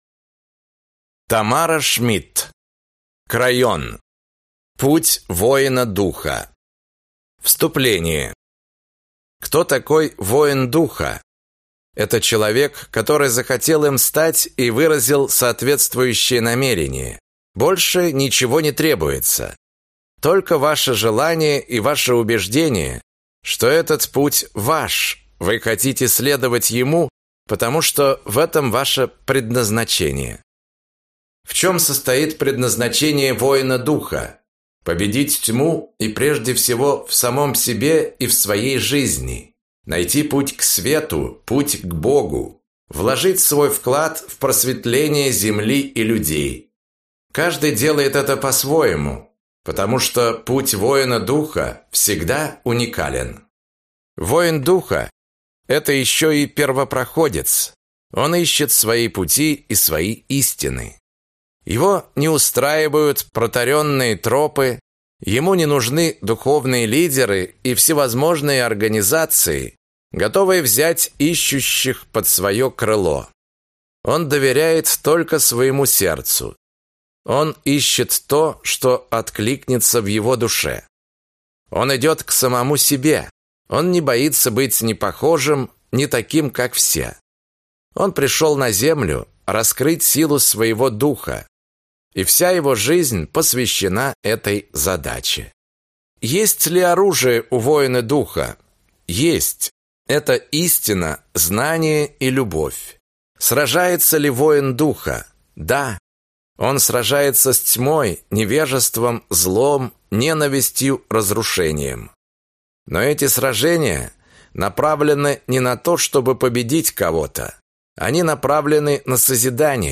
Аудиокнига Крайон. Путь воина Духа | Библиотека аудиокниг